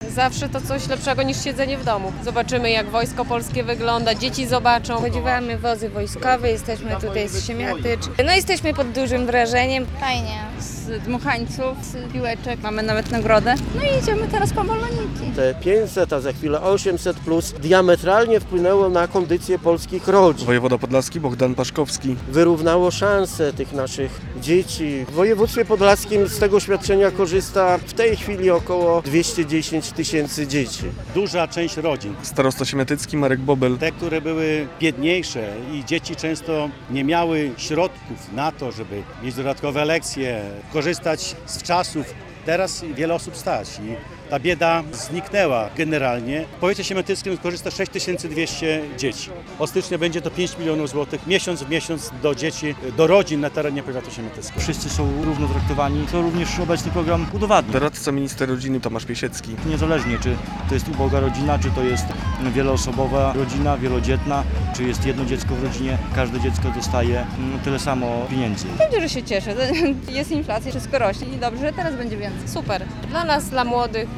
W Czartajewie trwa ostatni w naszym regionie "Piknik 800+" - relacja